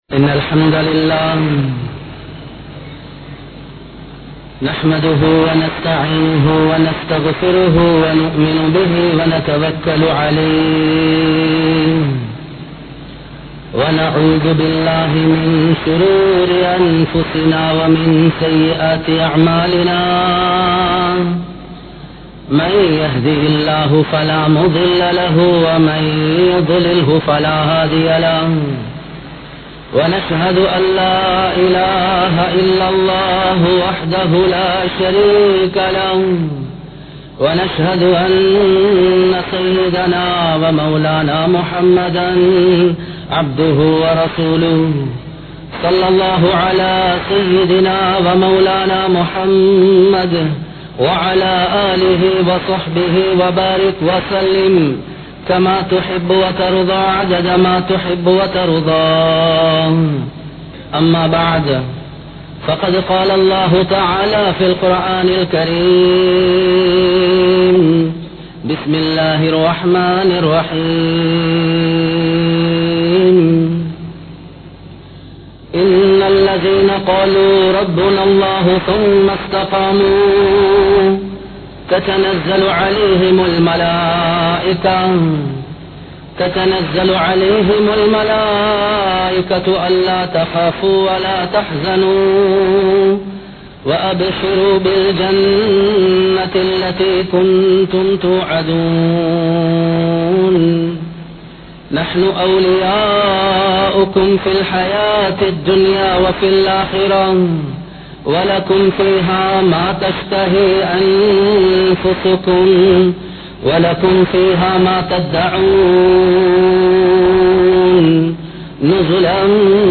Nabi(SAW)Avarhalin Mun Maathirihal (நபி(ஸல்)அவர்களின் முன்மாதிரிகள்) | Audio Bayans | All Ceylon Muslim Youth Community | Addalaichenai